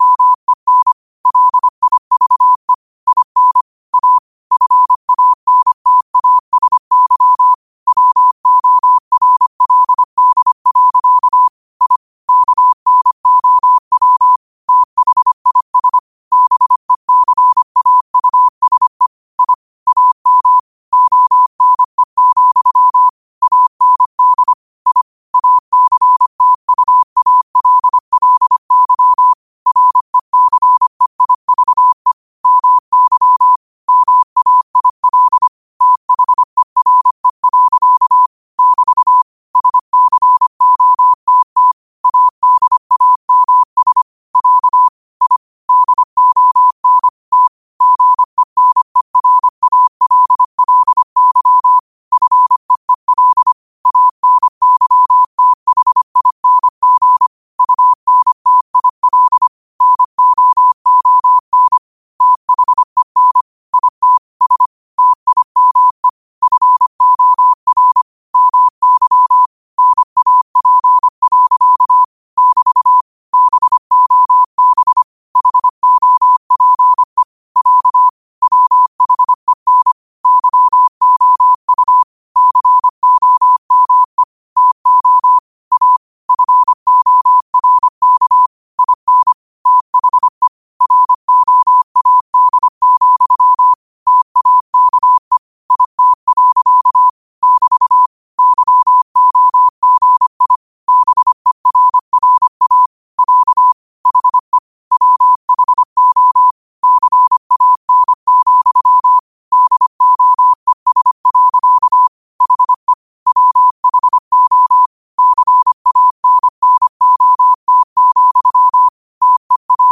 New quotes every day in morse code at 25 Words per minute.